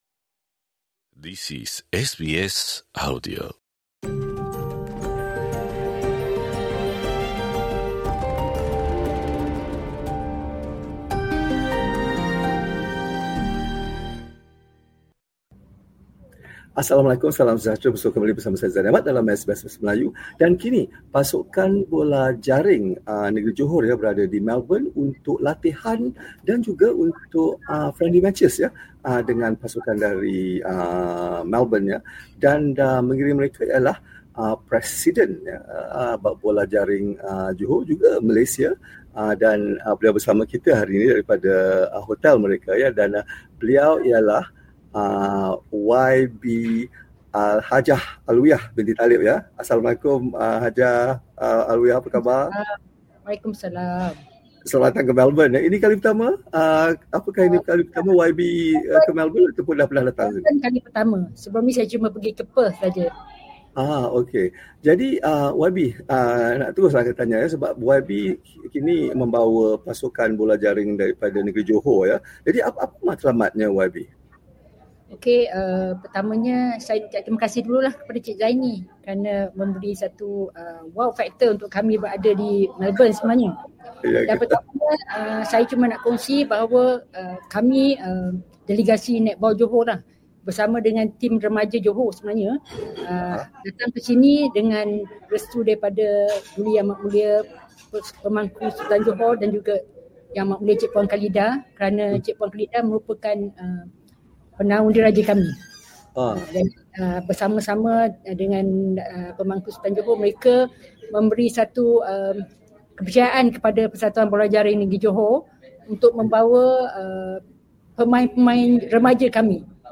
perbualan